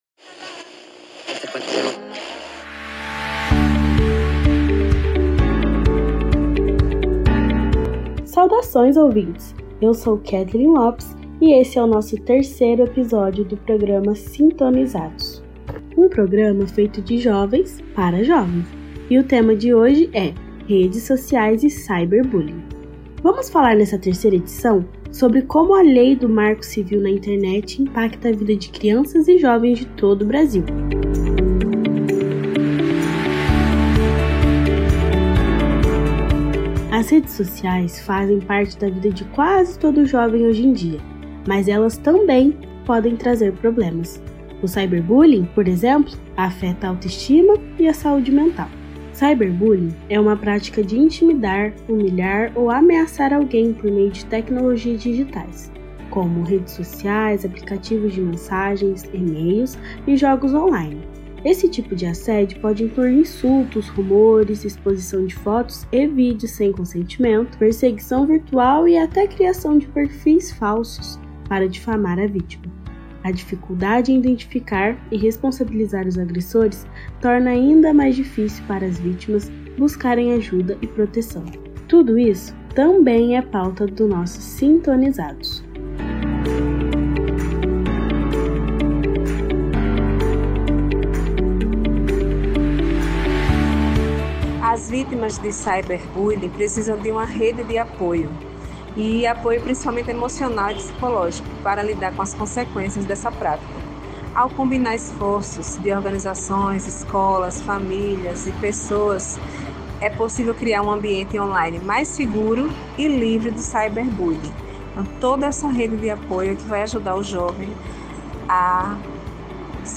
Nesta edição, vamos saber como a lei do marco civil da internet impacta os jovens de todo o Brasil. Sintonizados é um programa feito por jovens para jovens.
O programa vem de Guaxupé, interior de Minas Gerais.